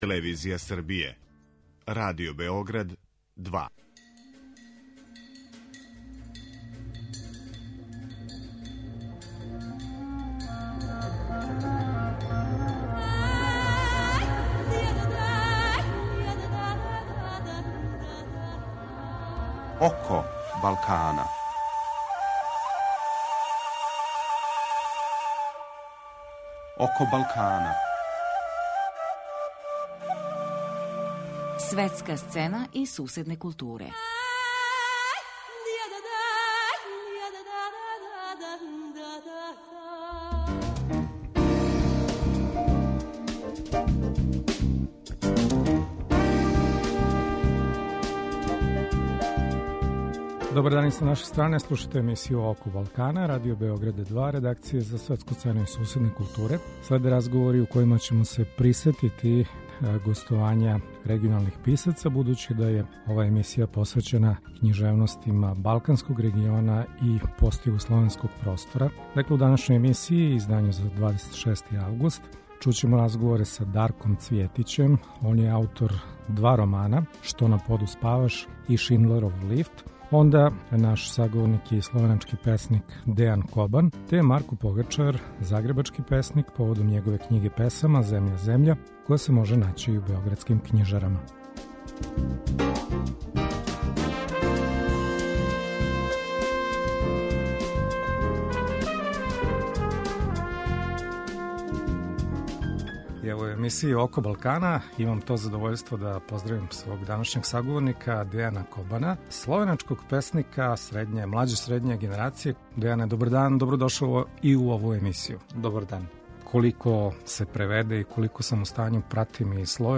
У данашњој емисији присетићемо се разговора које смо водили током ове године.